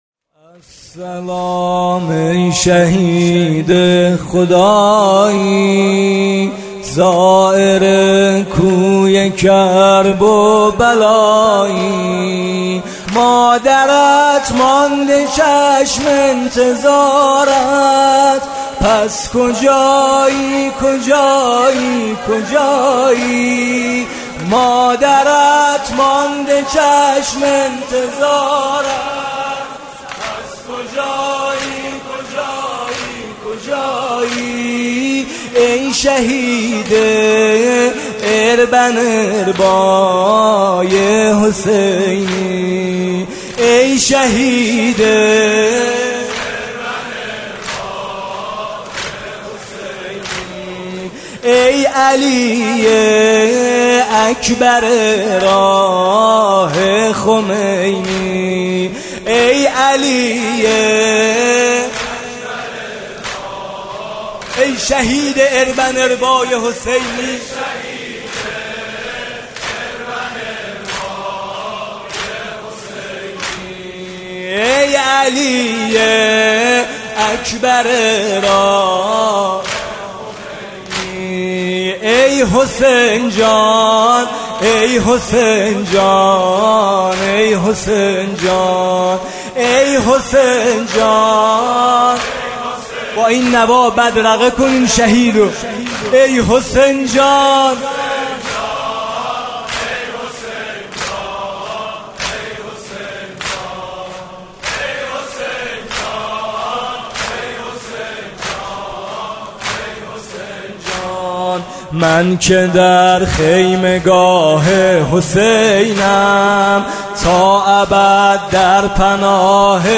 شب هشتم محرم ۱۳۹۸
music-icon زمینه: من کجای سپاه حسینم